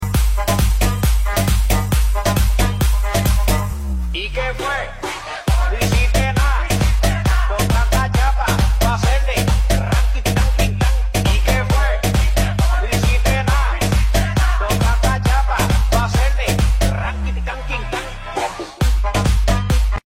পাট বাছা মেশিন 🥰🤭🥰🥰🥀🥀 sound effects free download